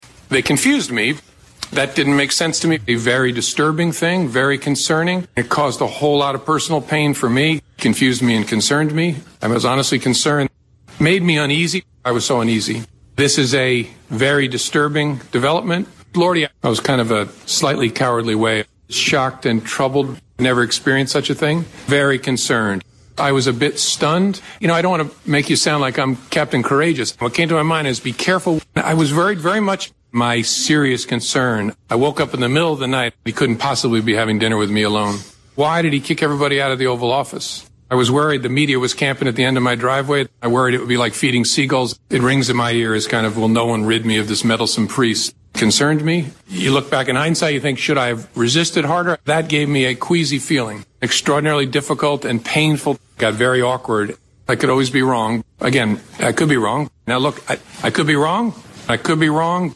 以下請你聽聽Comey在國會作證的錄音片段，看看這個困惑、軟弱、恐懼的人竟然是聯邦調查局的局長！